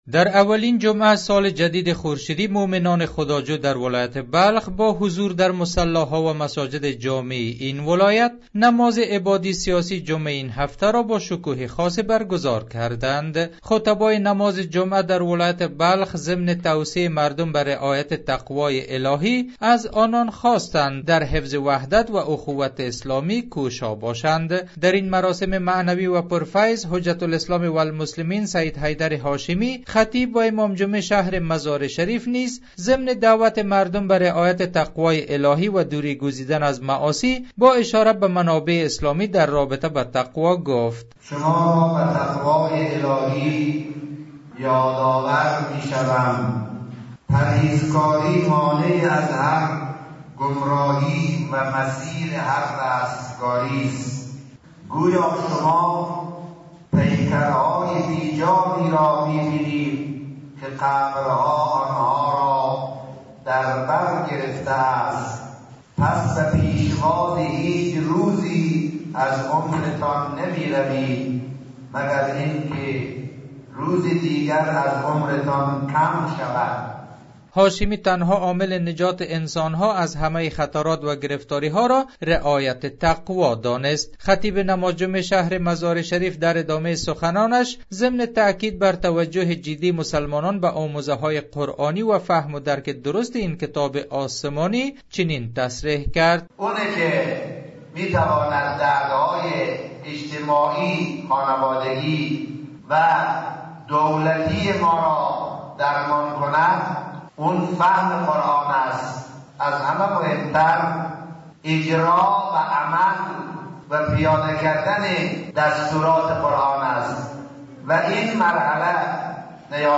از آیین عبادی و سیاسی نماز جمعه در مزار شریف گزارشی تهیه کرده است که میشنوید: